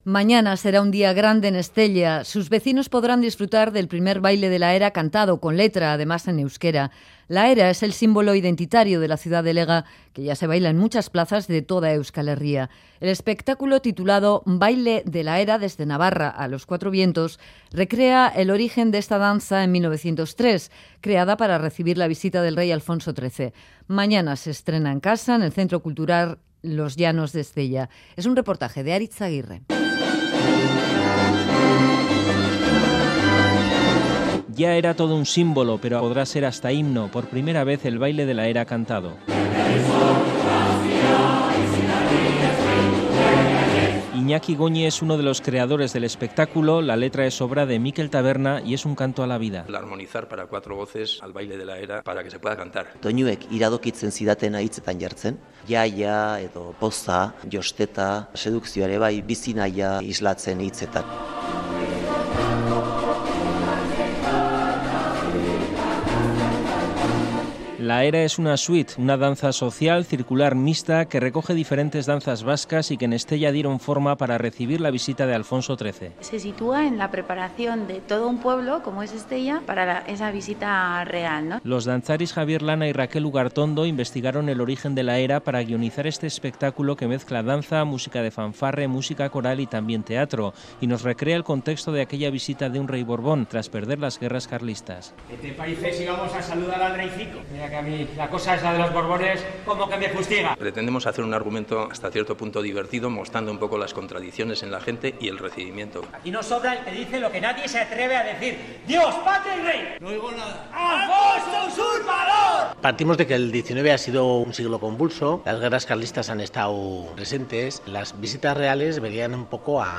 REPORTAJES